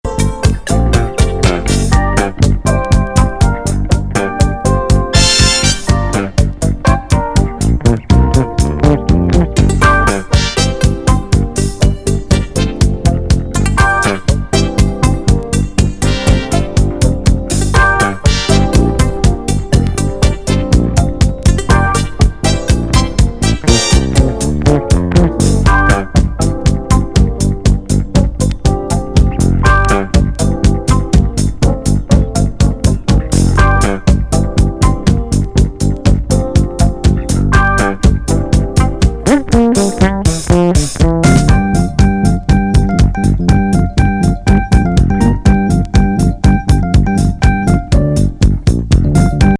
どちらも45RPM音質バッチリの04年好EDIT 12INCH!